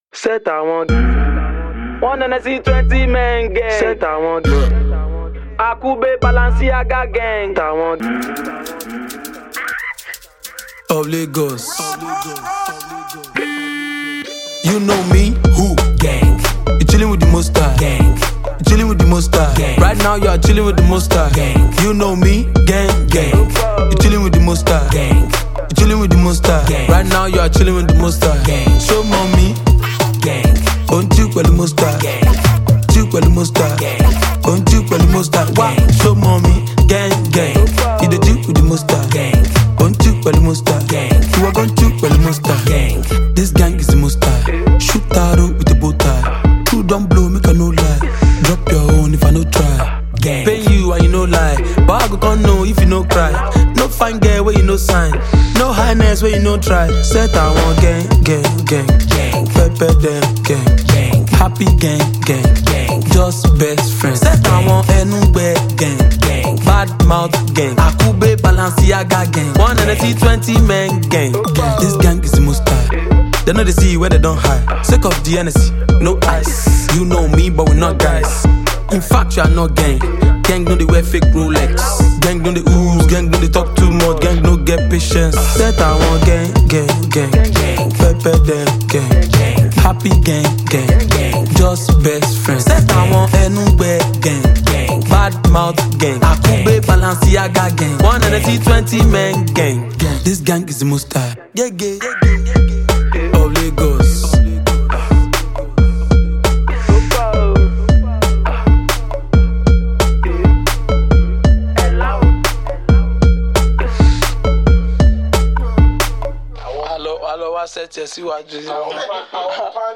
comic single
flows flawlessly on the mic
rhythmic bang